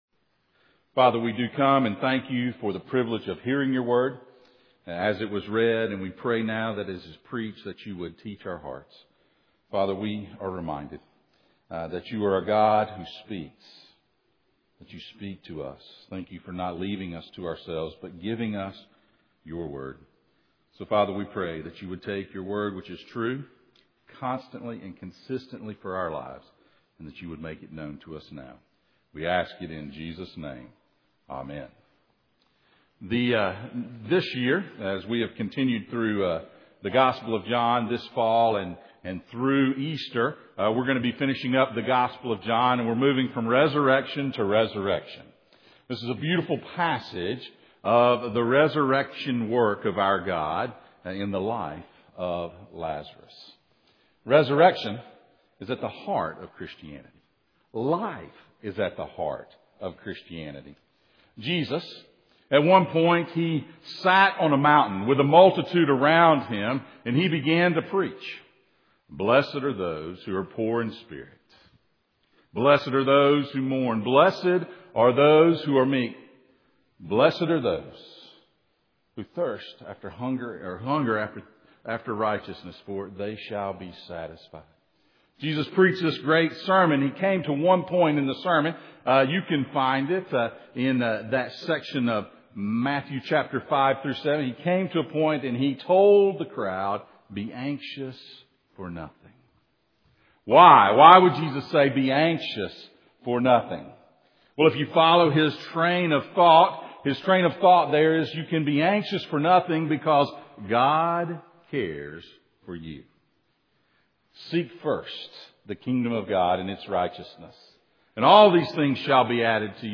The Word of God Sent Passage: John 11:1-44 Service Type: Sunday Morning « Shepherding In Perilous Times